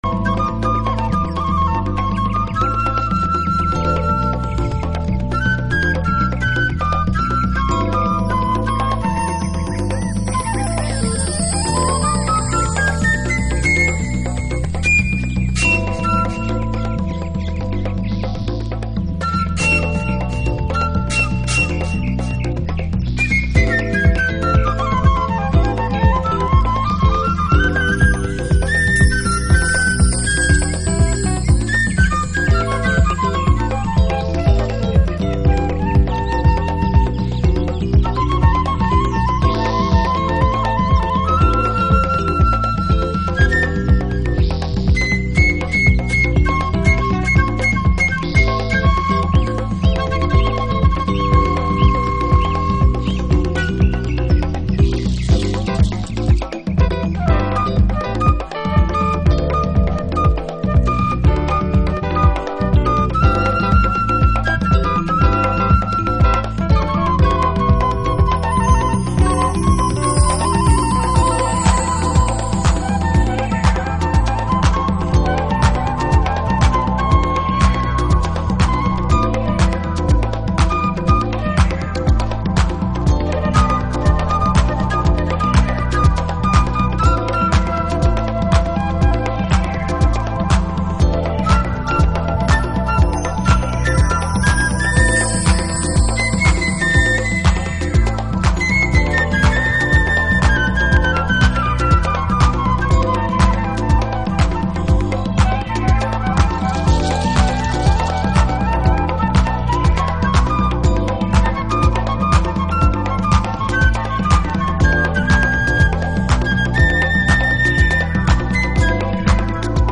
Early House / 90's Techno
トライバルジャズの大海原を演出するロフトスタイルセッション